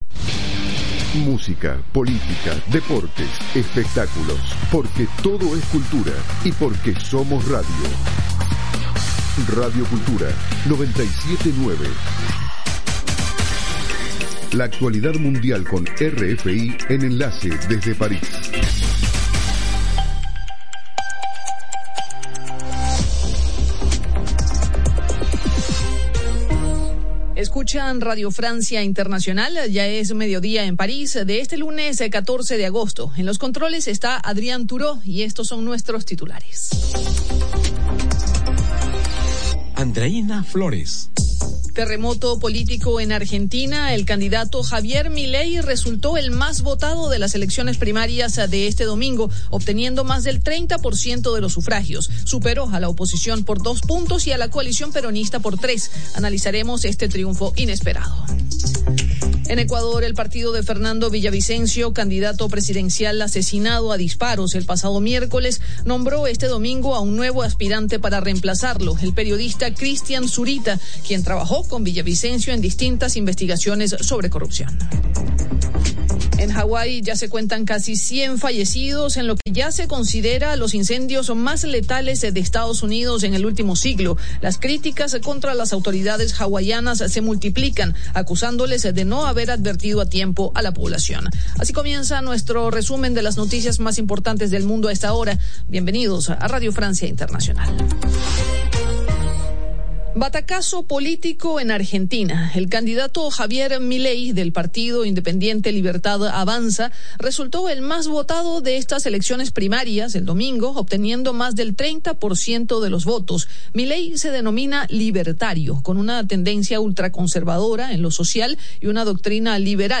Programa: RFI - Noticiero de las 07:00 Hs.